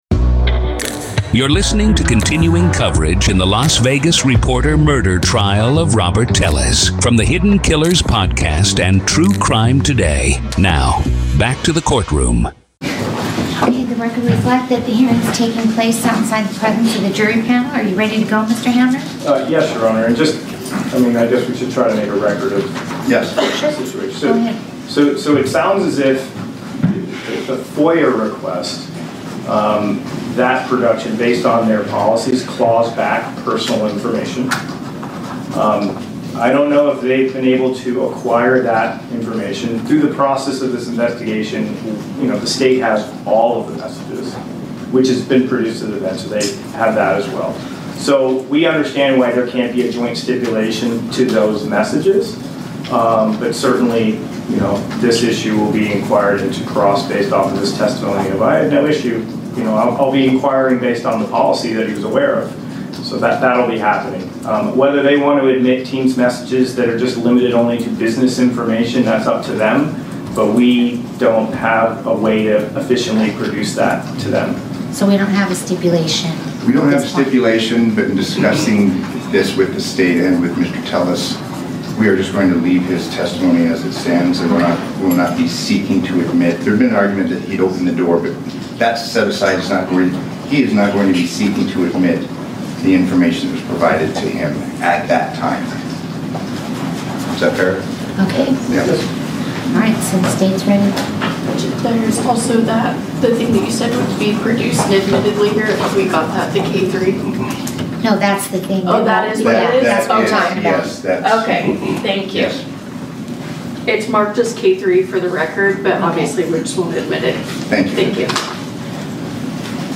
Telles Takes the Stand-Raw Court Audio-NEVADA v. Robert Telles DAY 7 Part 2